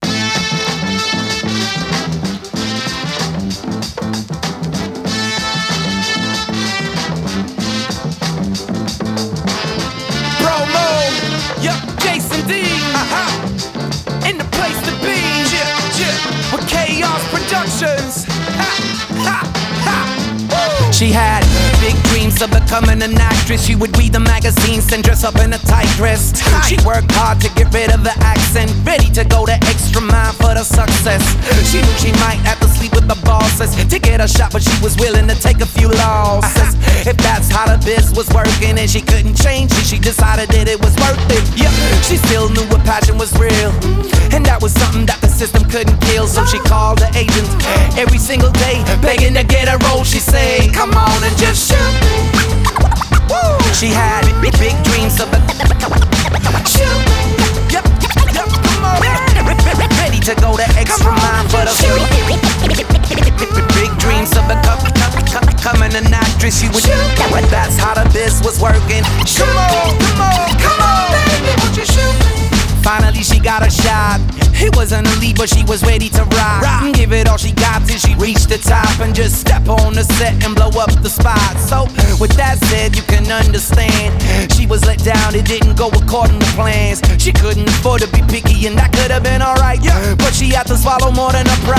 This is a track I recorded in Norway May 18 this year